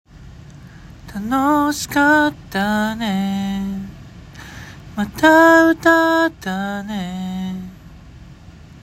③フェードアウト
だんだん声を小さくしていって終わらせるというテクニックです。
まずストレートに「あーーーーーー」と発声しながらだんだん声を小さくしていきます。